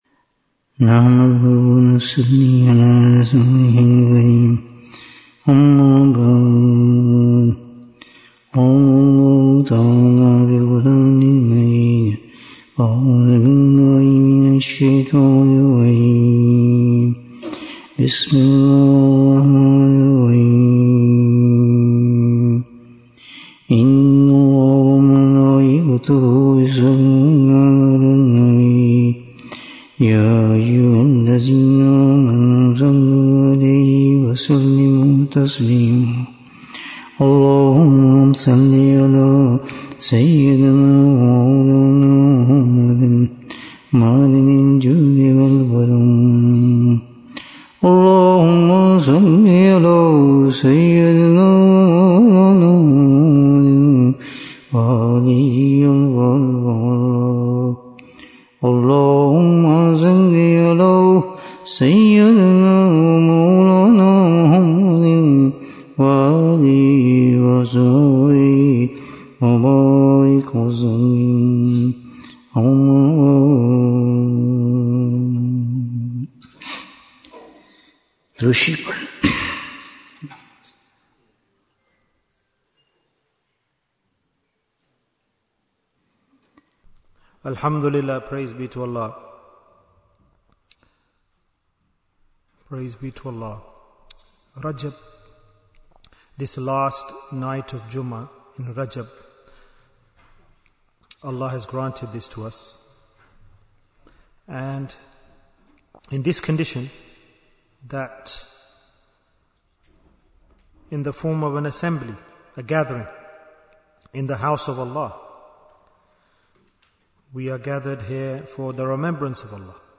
Protection from Calamities Bayan, 79 minutes15th January, 2026